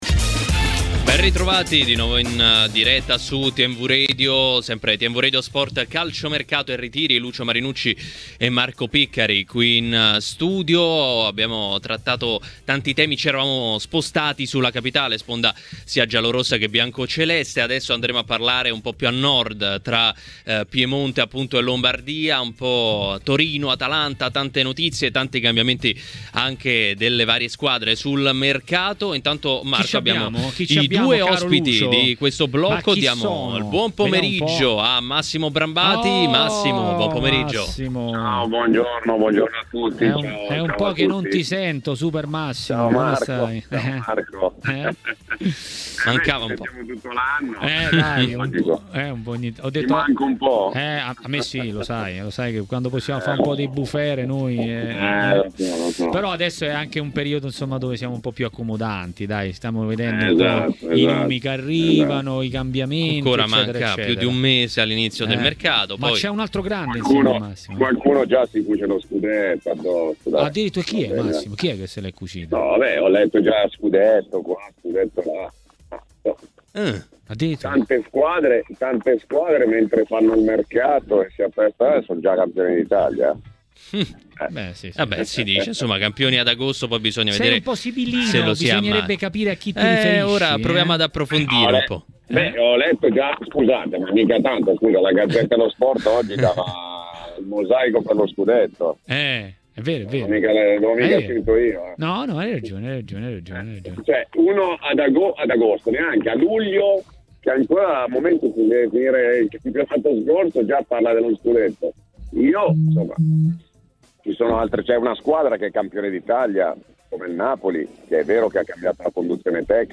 è intervenuto ai microfoni di Tmw Radio per parlare di Frattesi.